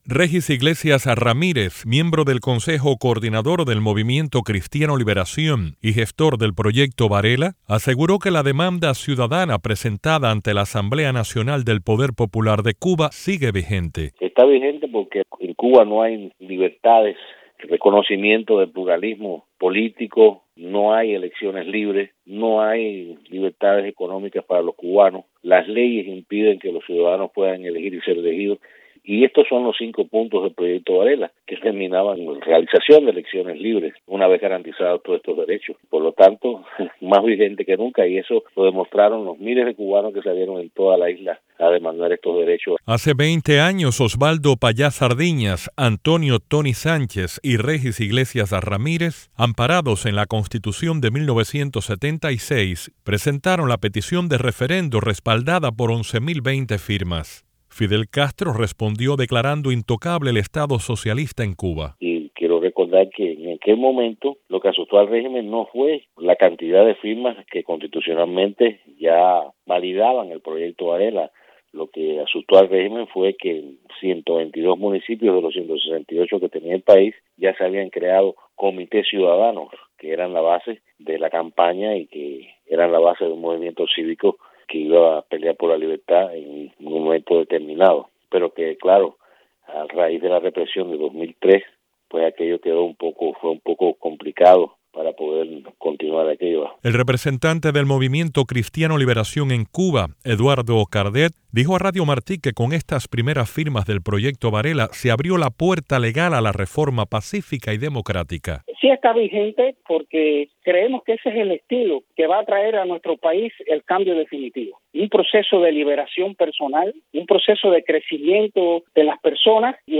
Con un reporte